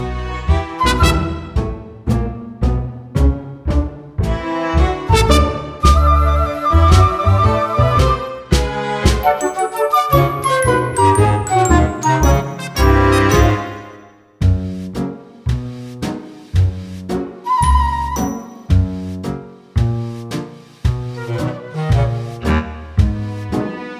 Key of G Major Edit 3 Christmas 3:08 Buy £1.50